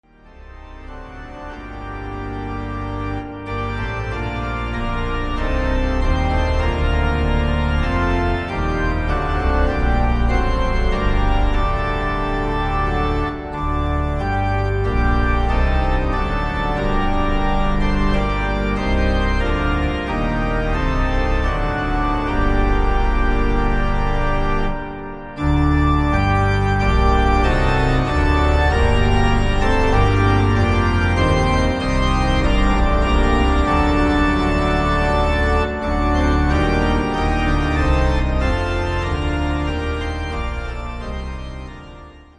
ORGAN HYMN ACCOMPANIMENT ON CD